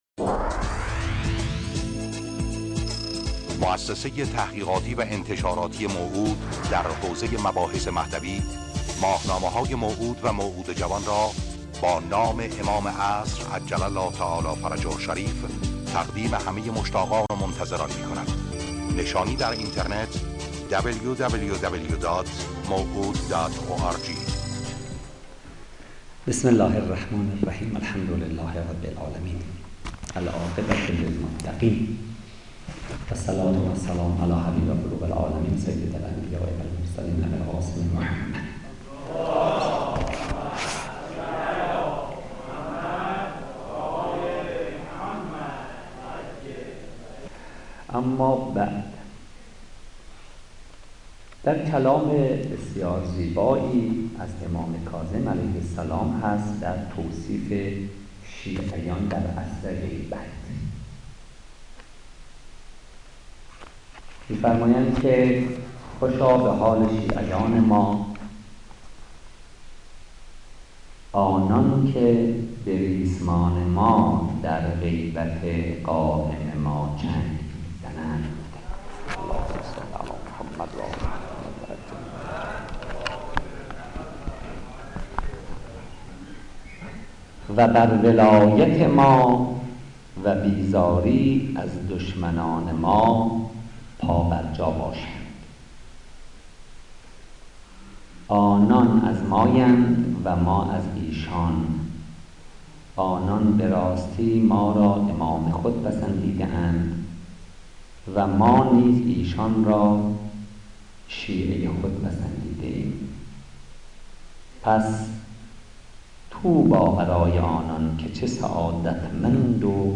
سخنان
در شصت و یکمین نشست از سلسله نشت‌های فرهنگ مهدوی